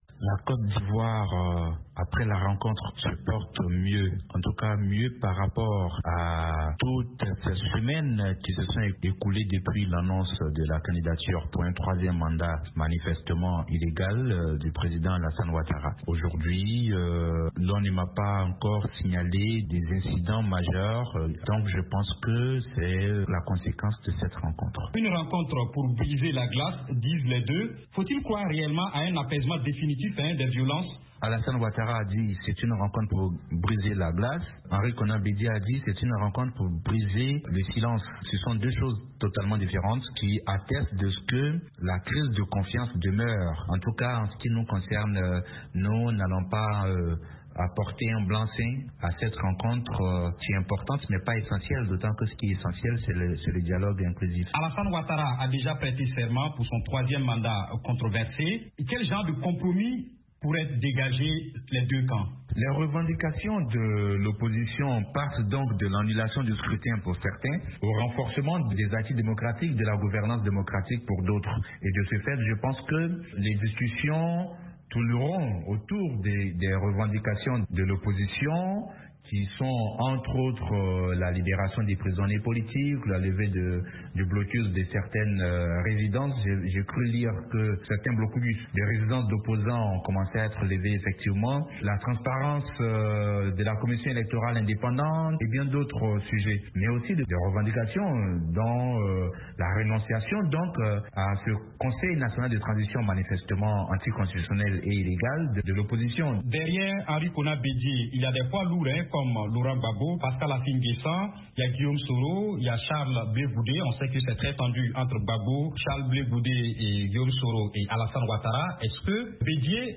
analyste politique.